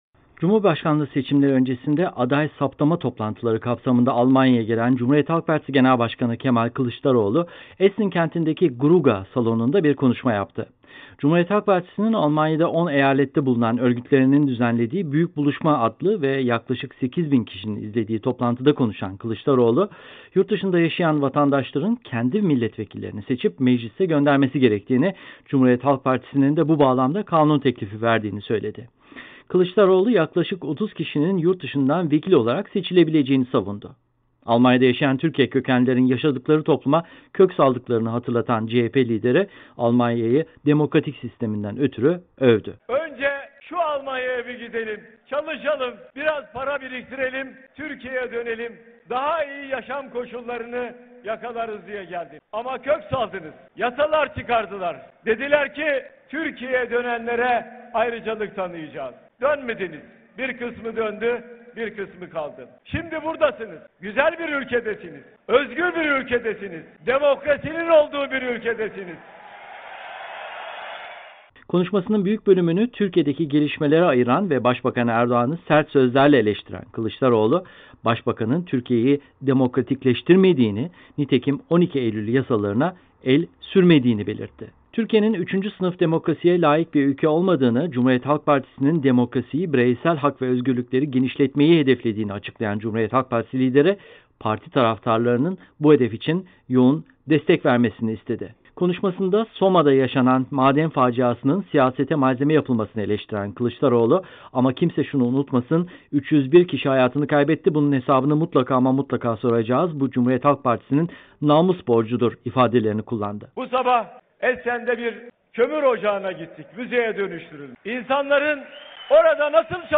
CHP’nin Almanya’da 10 eyalette bulunan örgütlerinin düzenlediği toplantıda konuşan Genel Başkan Kemal Kılıçdaroğlu, yurtdışında yaşayan vatandaşların kendi milletvekillerini seçip Meclis’e göndermesi gerektiğini, CHP’nin de bu bağlamda kanun teklifi verdiğini söyledi